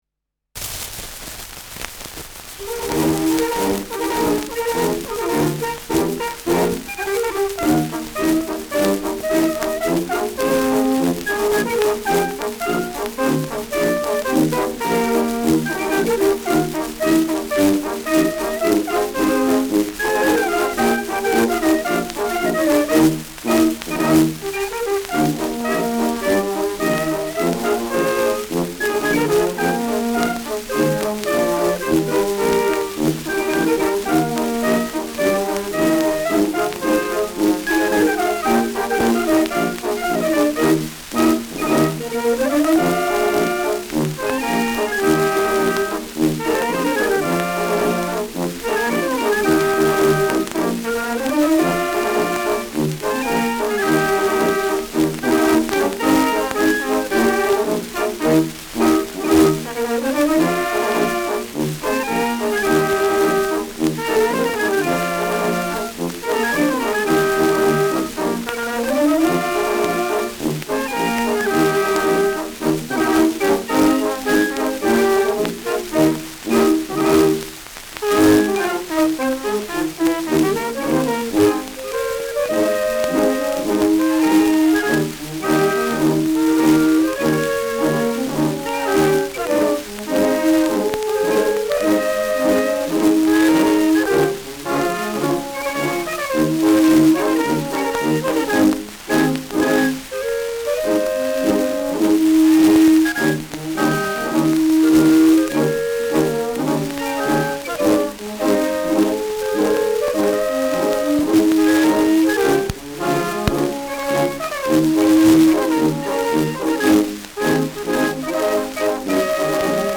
Schellackplatte
präsentes Rauschen : abgespielt : leiert : präsentes Nadelgeräusch : präsentes Knistern : vereinzelt leichtes Knacken
[Nürnberg] (Aufnahmeort)